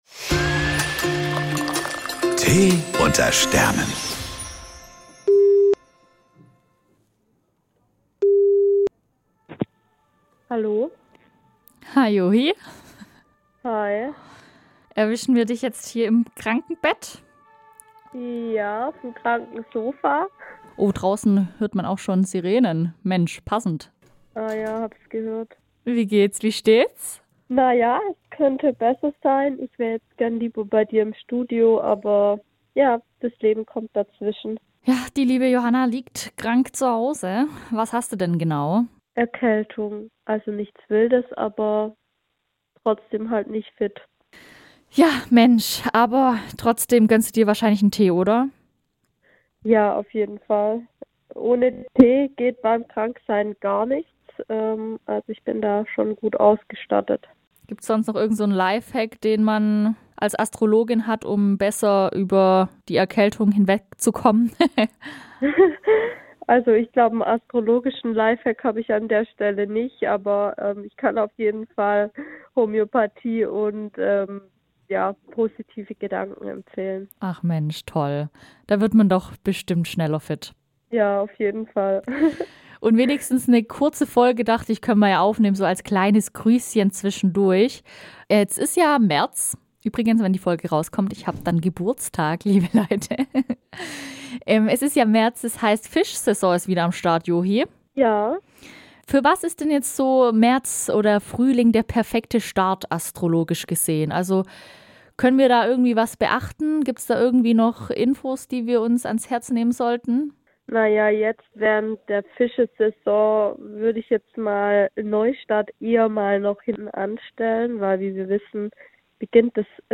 Ein kleiner Überraschungsanruf